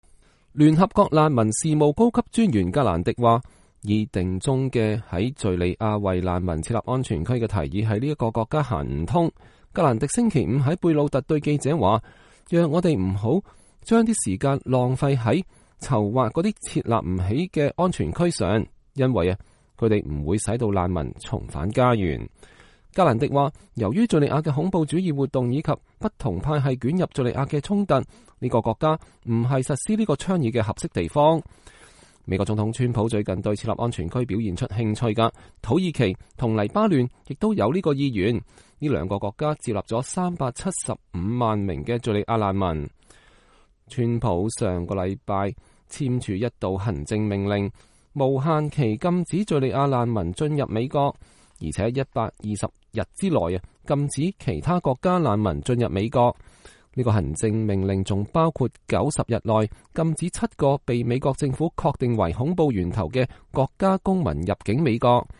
格蘭迪在貝魯特和記者講話(2017年2月3日)